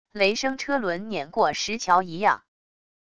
雷声车轮碾过石桥一样wav音频